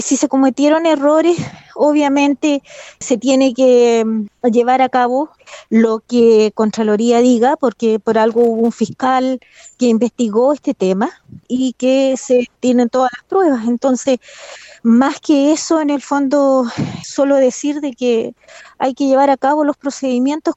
La concejal Leonila Sáez (UDI), dijo que ahora todo lo que queda por hacer es aplicar las sanciones que propone Contraloría.